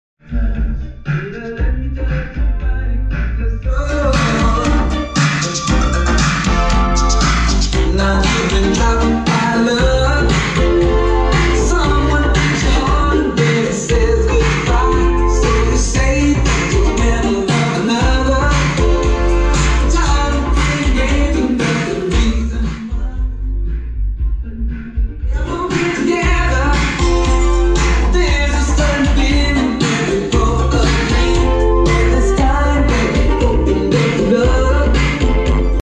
Go pro sound sucks